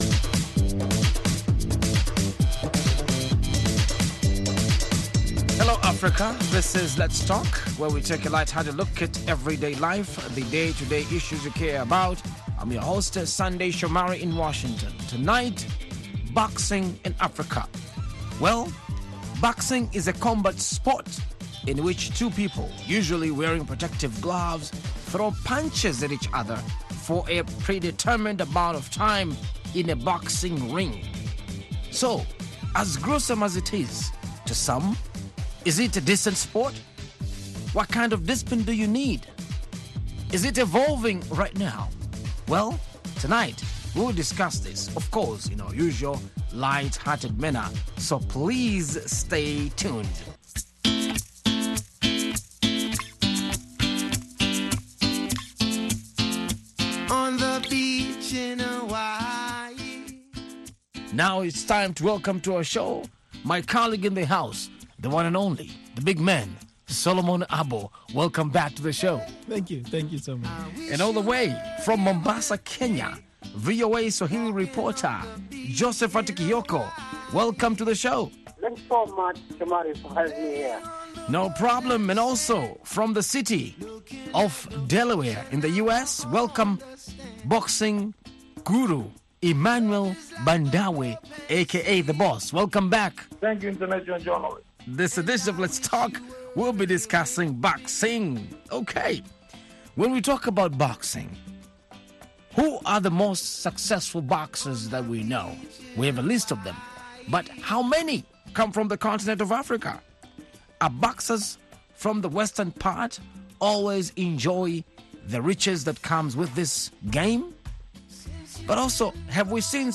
Let's Talk is an interactive discussion program about lifestyle issues.